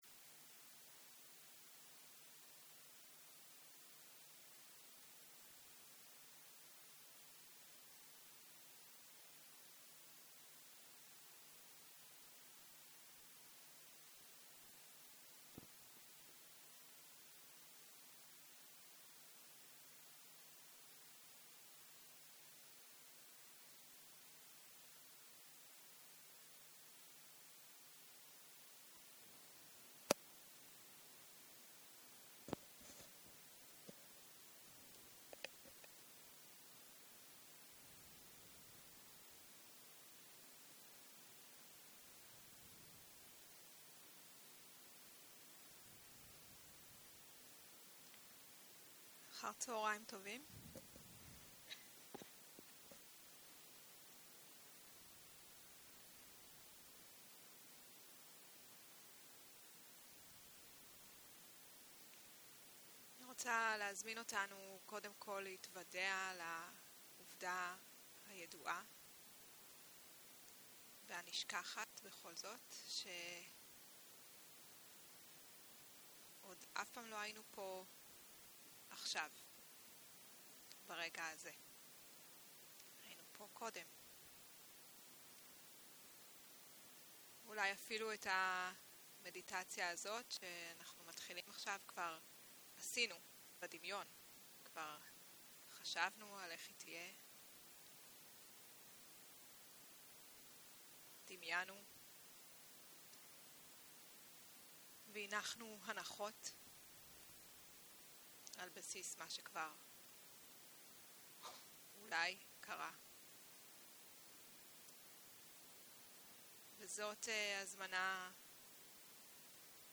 סוג ההקלטה: מדיטציה מונחית